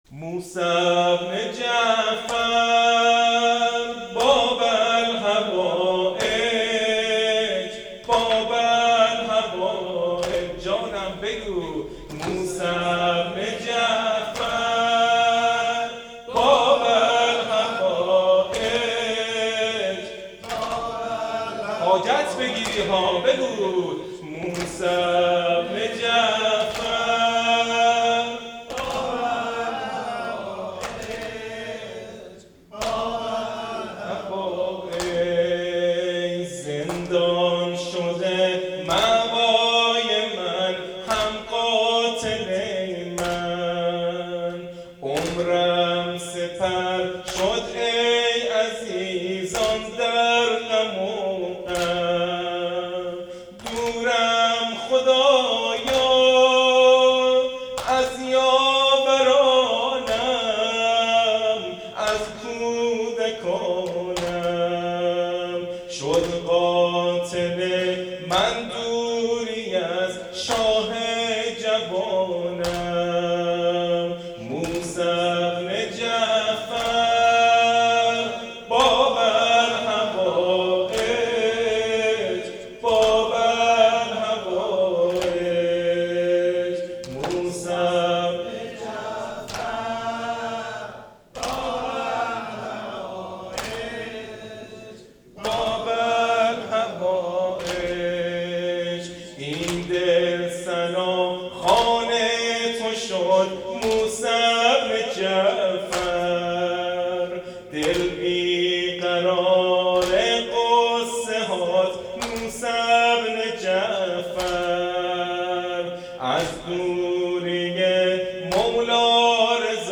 زمزمه شهادت امام کاظم علیه السلام